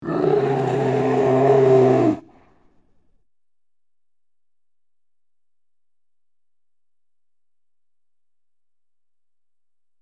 Download Grizzly Bear sound effect for free.
Grizzly Bear